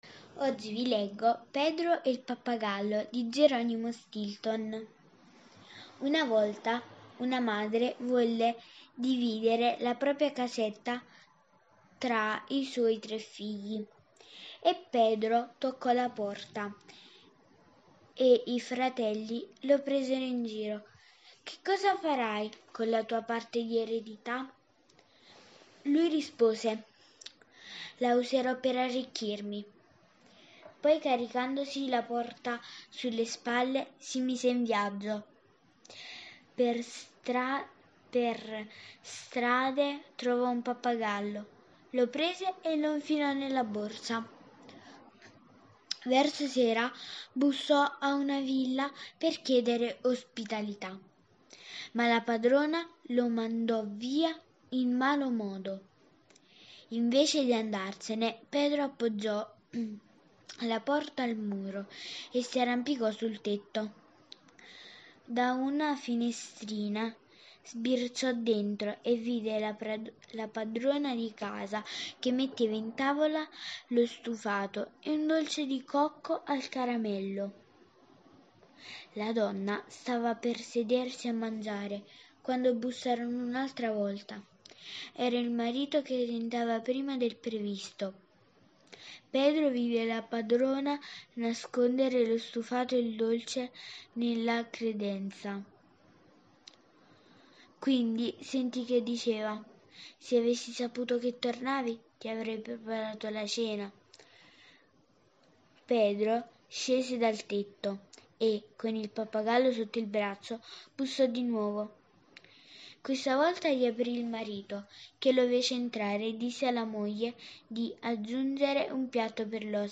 Questa sera vi leggo la fiaba di origine brasiliana “Pedro e il pappagallo” tratto dal libro di fiabe “le fiabe più belle del mondo” di Geronimo Stilton